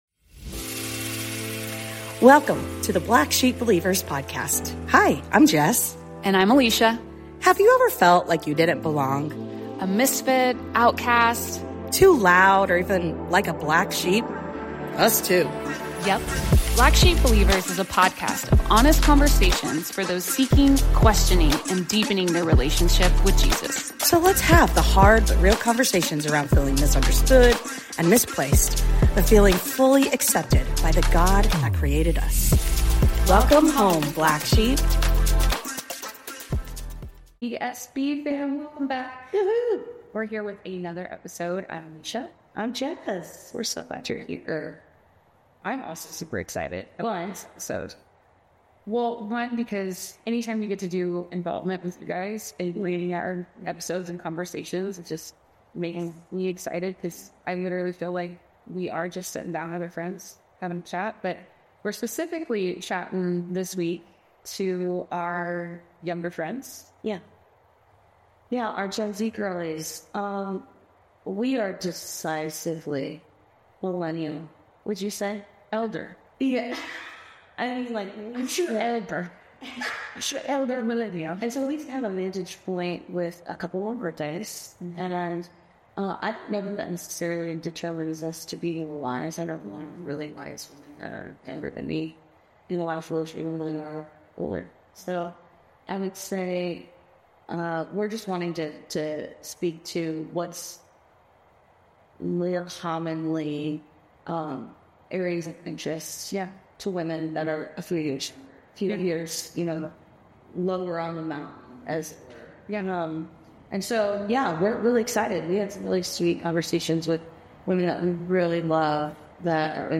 In this episode, we engage in a heartfelt conversation aimed at Gen Z listeners, discussing this generation's unique perspectives and challenges. We explore the evolution of friendships into adulthood, the importance of reading the Bible with intention, and the dynamics of godly relationships and dating.